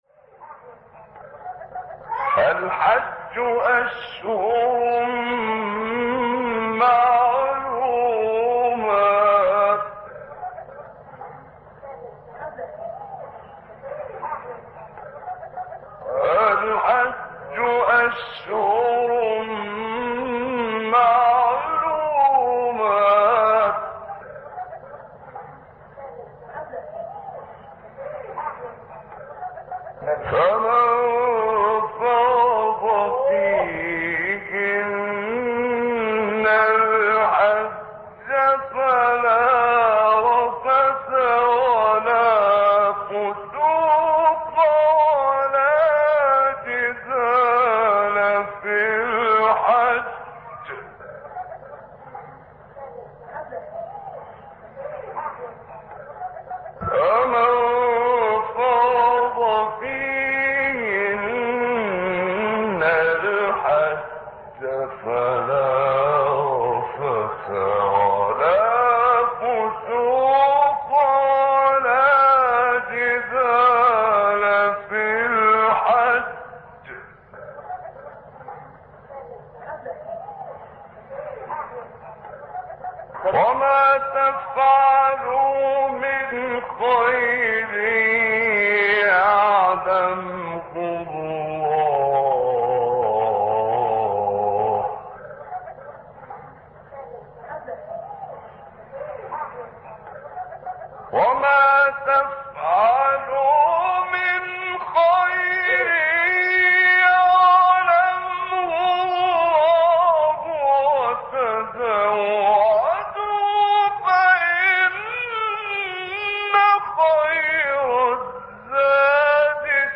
آیه 197 سوره بقره محمد عمران | نغمات قرآن | دانلود تلاوت قرآن